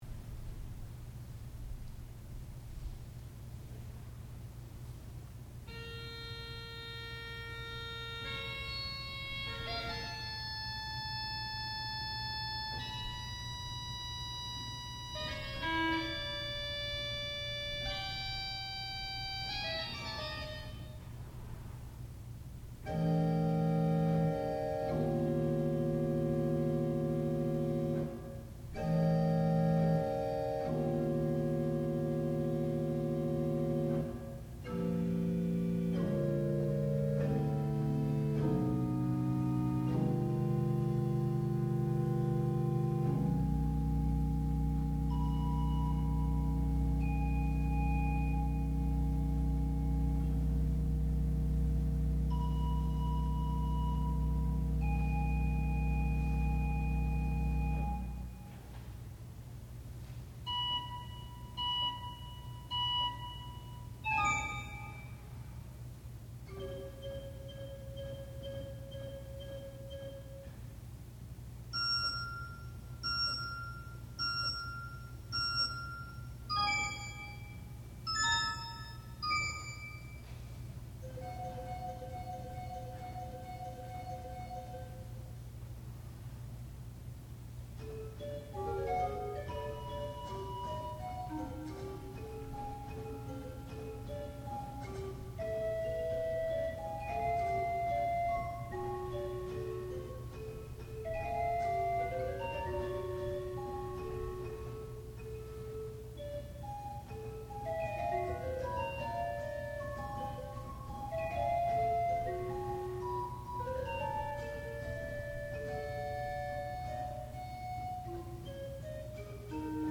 sound recording-musical
classical music
Graduate Recital
organ